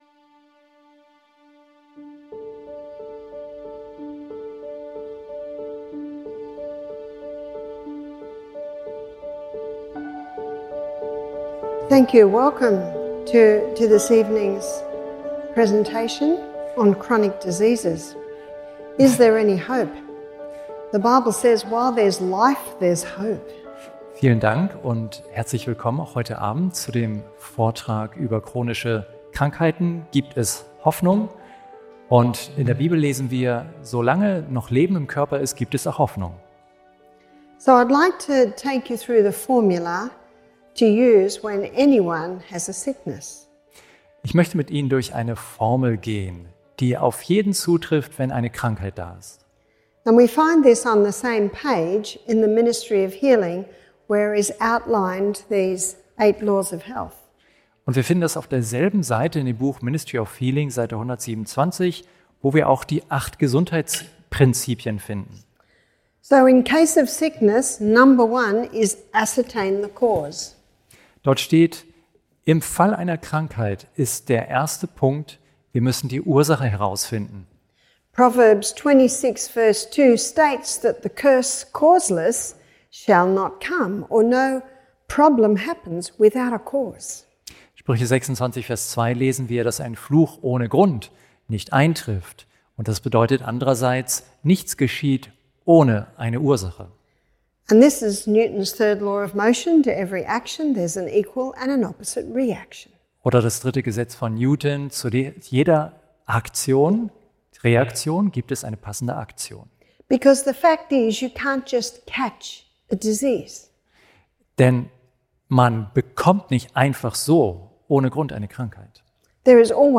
In diesem fesselnden Gesundheitsvortrag werden Ursachen und Lösungen für chronische Krankheiten thematisiert. Mit inspirierenden Beispielen und praktischen Tipps wird aufgezeigt, wie Lebensstiländerungen, gezielte Ernährung und bewusstes Atmen Heilung fördern können.